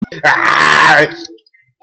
Play the Aaargh sound button instantly. 2-second meme soundboard clip — free, in-browser, no signup, no download required.
A classic pirate-style 'argh!' or frustrated growl.
aaargh.mp3